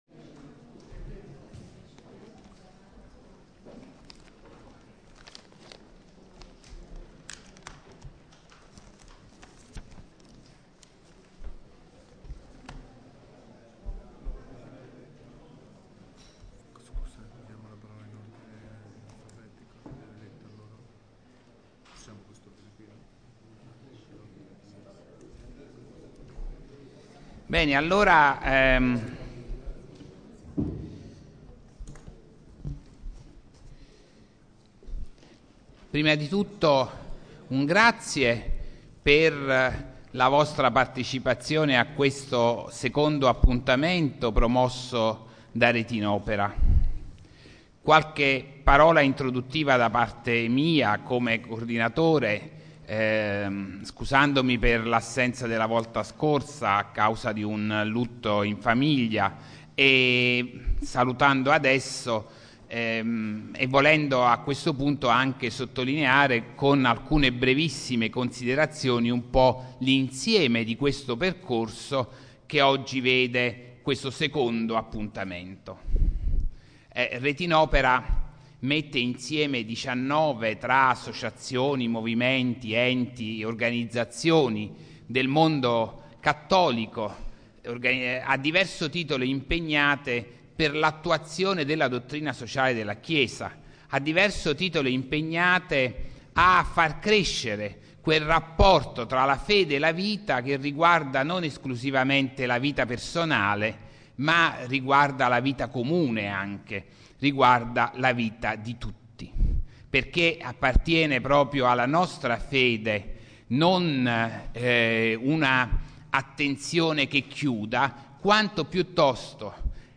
Audio Seminario I migranti: fenomeno globale, integrazione possibile